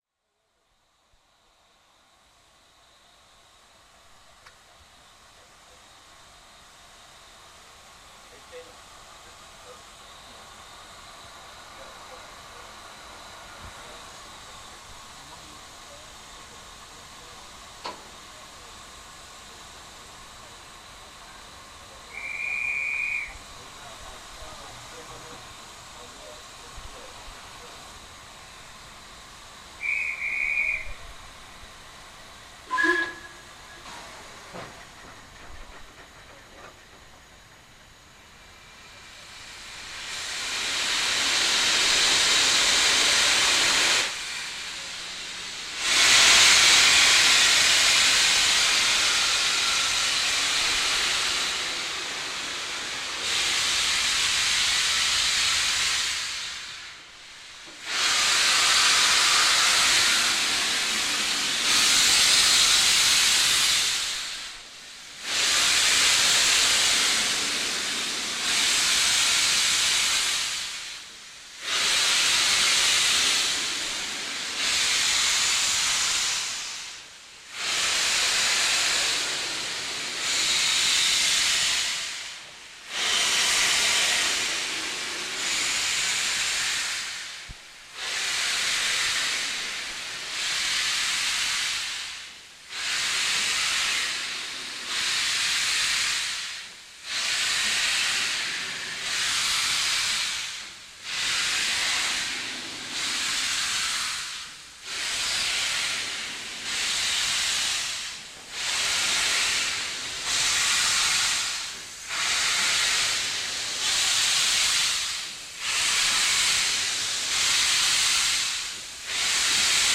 Soundaufnahmen von Dampflokomotiven, nur hochwertige, nur vollständige und alle zum Downloaden in guter (stereo)-Qualität